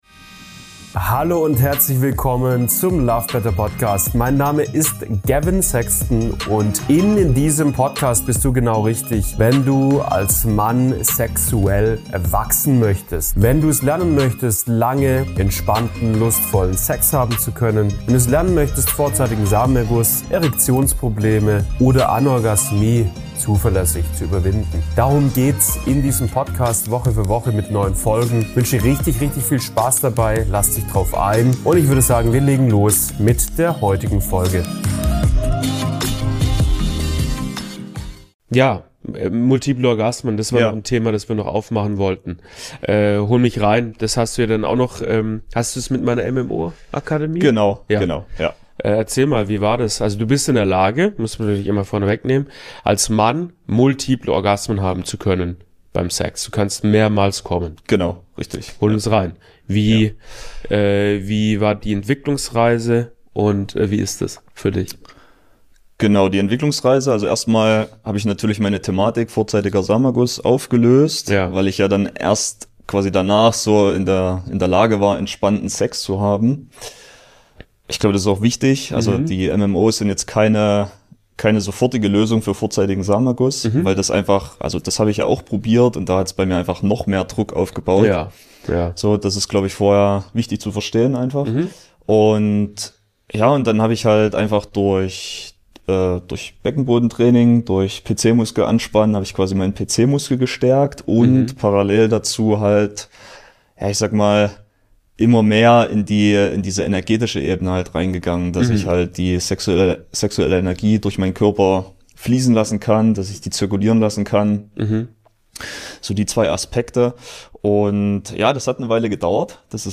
Multiple Orgasmen als Mann haben können - so geht’s! (Klienteninterview